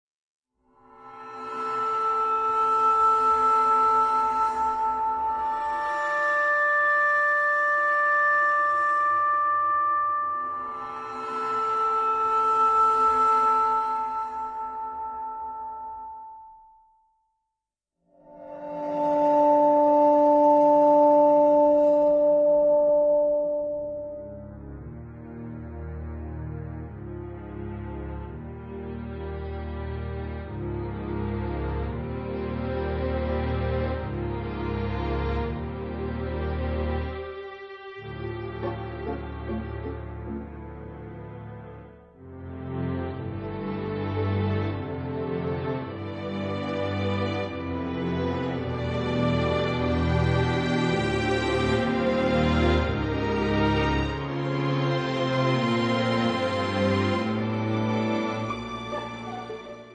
saxofono soprano
ritmi drum ‘n'bass
dall'intonazione veemente e fortemente ritmica.